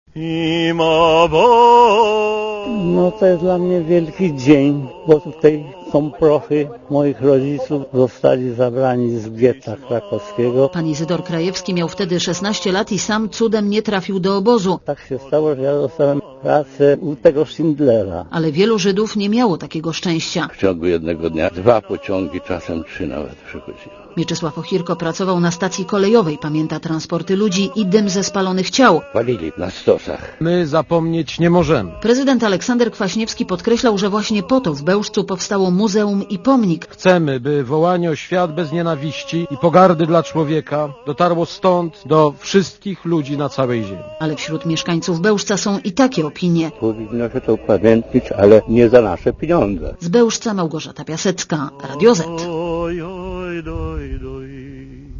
reporterki Radia ZET